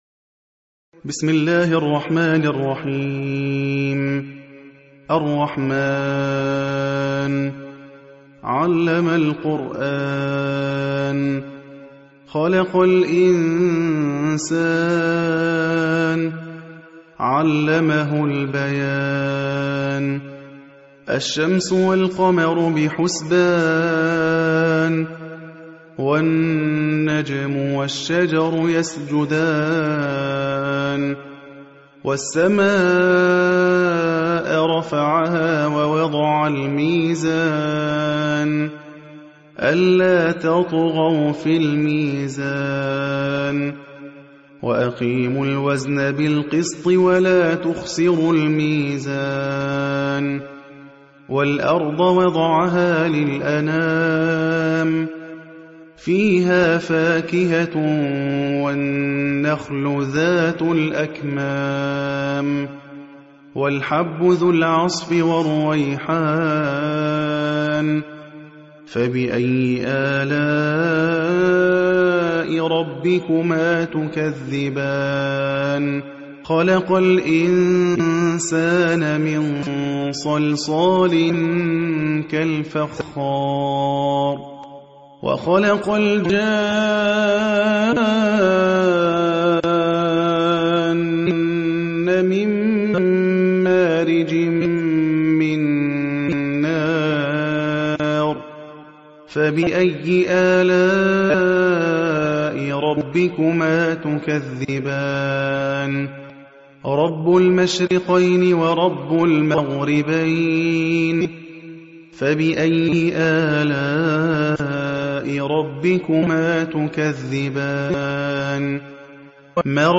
(روایت حفص)